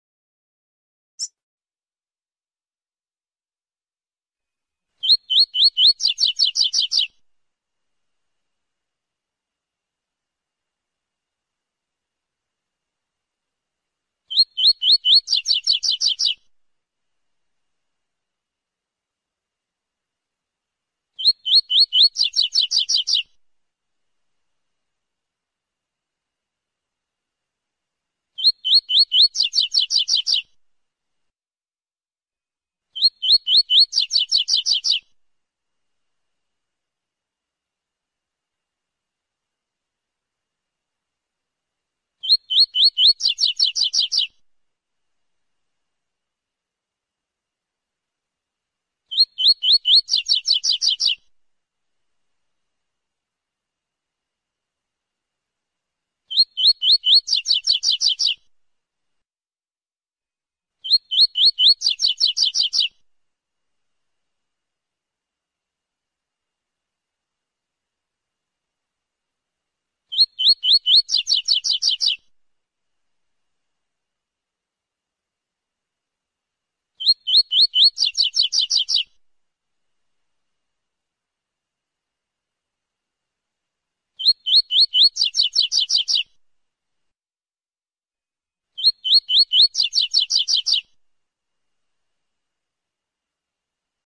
Coleiro Tui Tui Zel Zel Sound Effects Free Download